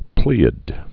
(plēəd, -ăd, plī-)